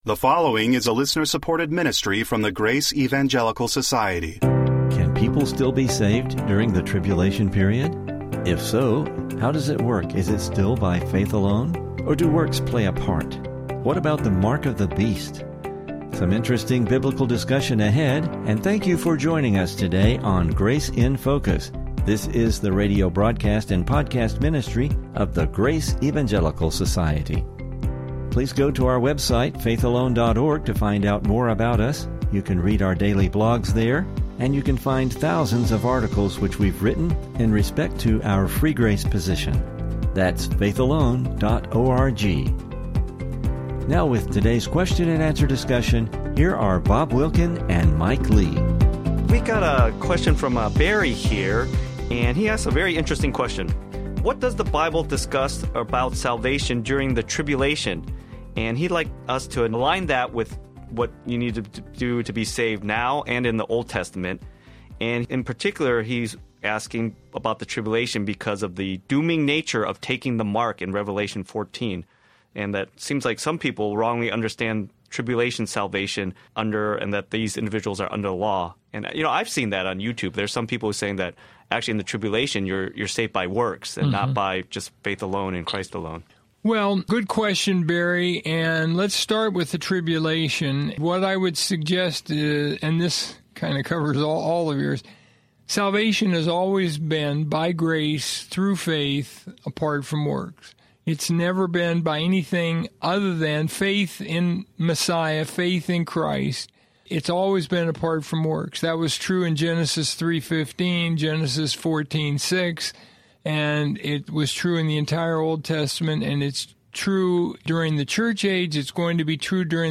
Is it by works or maybe partly by works? How does the “mark of the beast” work? Please listen for some interesting Biblical discussion regarding this subject!